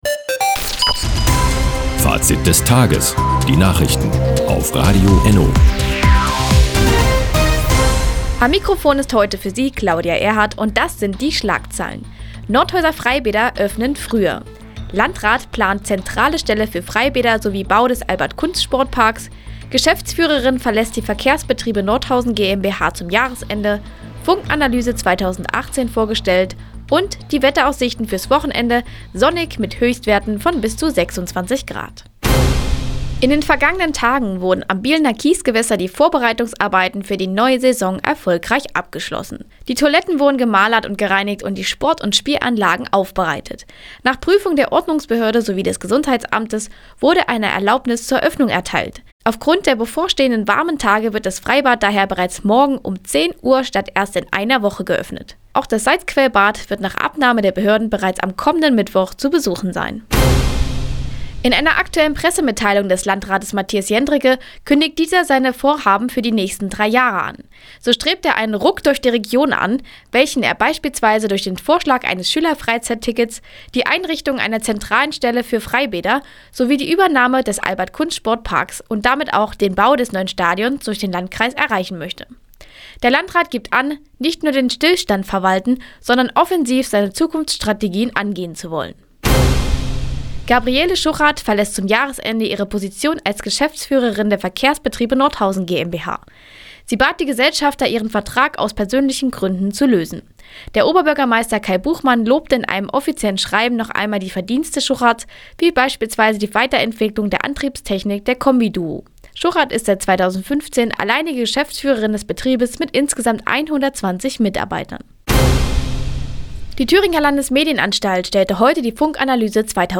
Die tägliche Nachrichtensendung ist jetzt hier zu hören...
Nachrichten